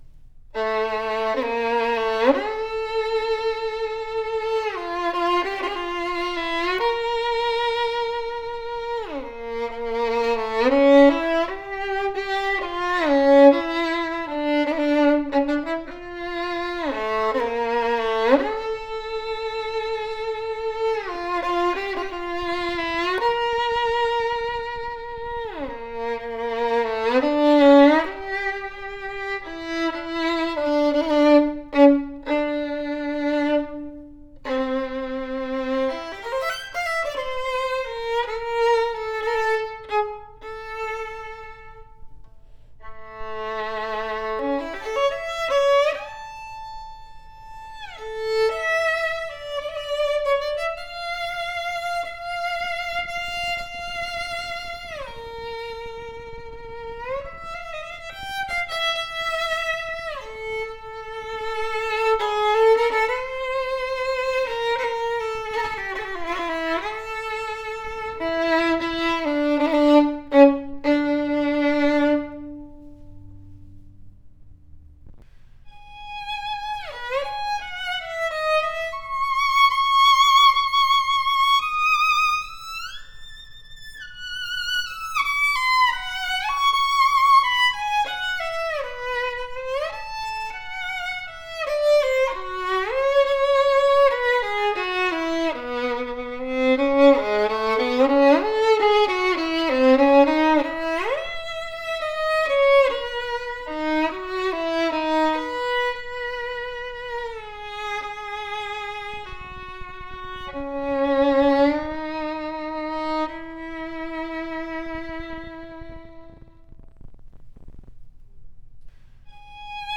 Superior mellow and warm sounding violin with sweet and rounded voice, well projected in the slightly darker, strong voice! Very mature lower register with sufficient depth, open and full mid register. Sweet brilliant E string. An EXTREMELY EASY TO PLAY VIOLIN that vibrates and sings with great projection!